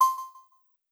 Success12a.wav